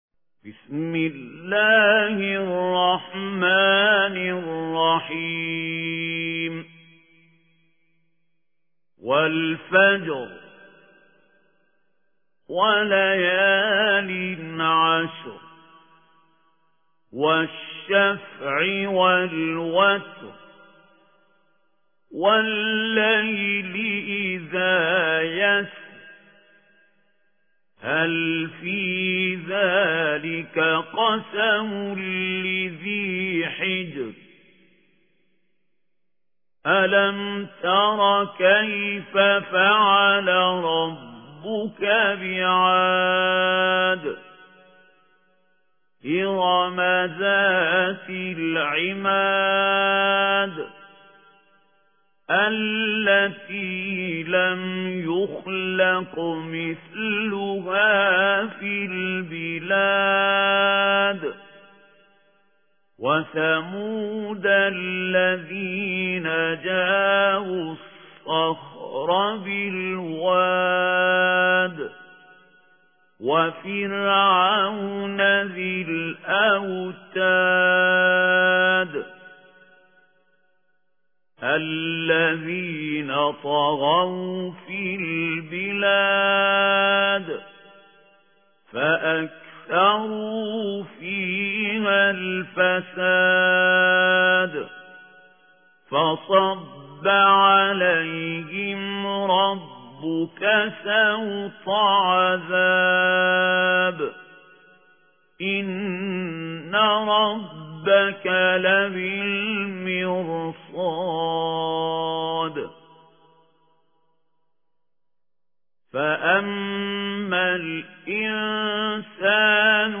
صوت | تلاوت ترتیل «سوره فجر» با صوت خلیل الحصری
در قسمت دهم، تلاوت ترتیل سوره فجر را با صوت خلیل الحصری، قاری مصری می‌شنوید.
برچسب ها: خلیل الحصری ، سوره فجر ، تلاوت ترتیل ، ماه محرم